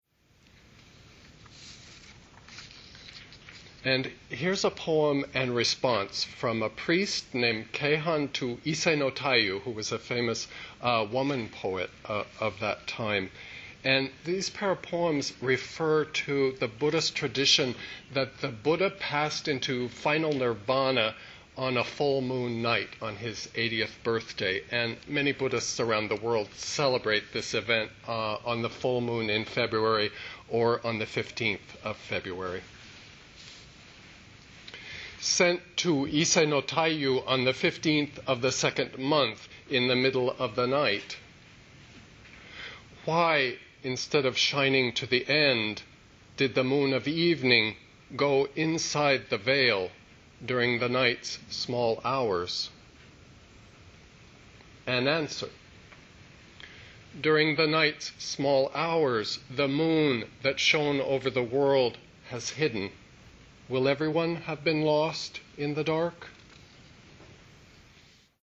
and “An Answer” live at the University of Maine Farmington, November 15, 2007. This pair of poems are Japanese